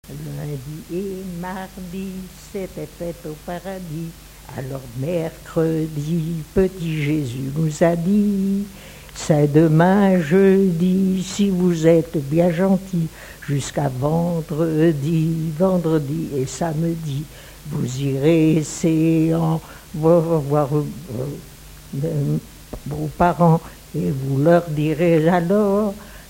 Localisation Mareuil-sur-Lay
Pièce musicale inédite